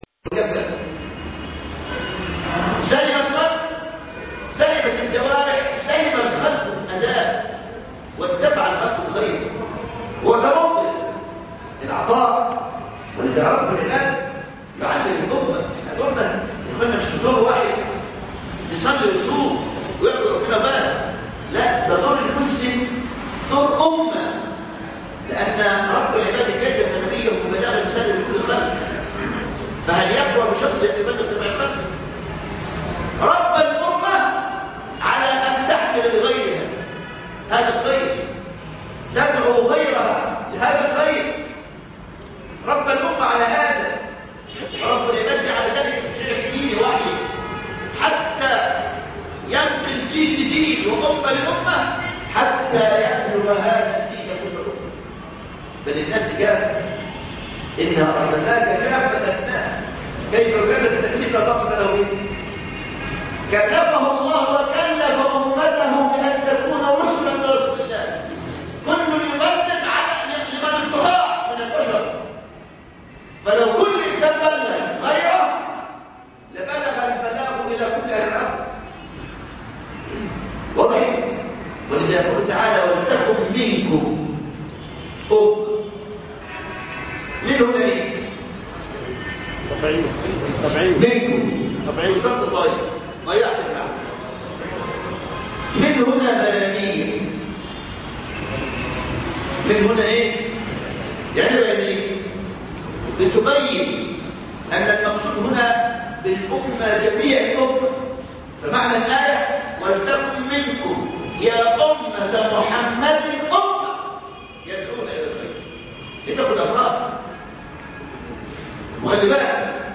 الدرس 7 (بعض وجوه الإعجاز في الخلق